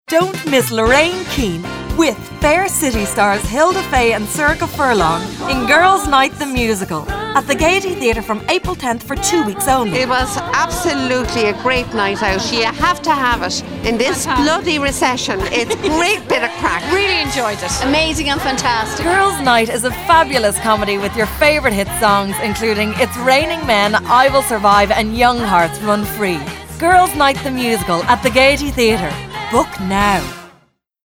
Husky tones, gravelly voice, deep timber, mature, baritone, sexy
Sprechprobe: Industrie (Muttersprache):